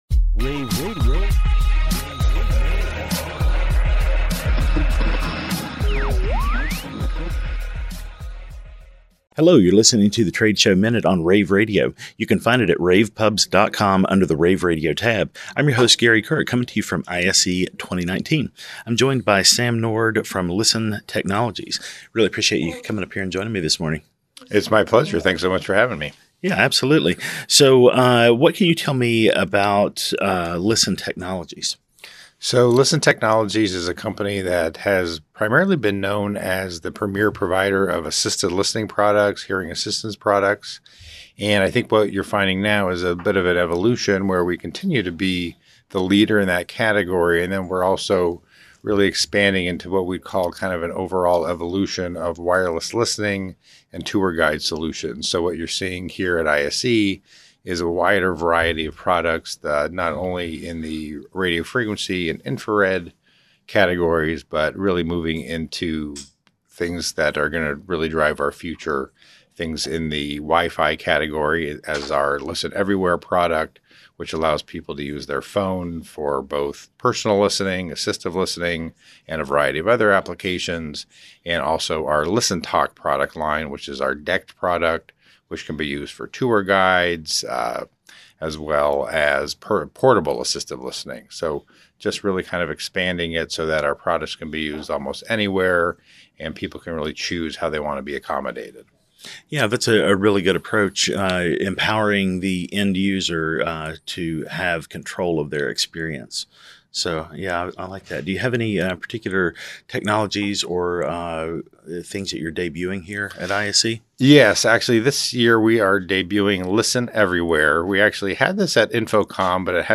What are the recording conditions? February 8, 2019 - ISE, ISE Radio, Radio, rAVe [PUBS], The Trade Show Minute,